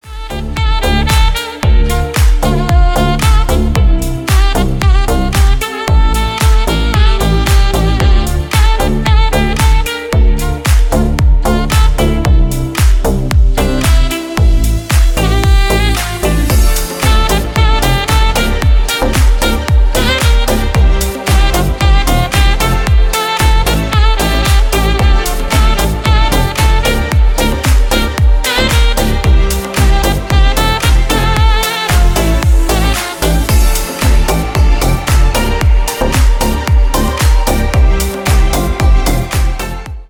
• Качество: 256, Stereo
deep house
без слов
Саксофон
Красивый кавер на саксофоне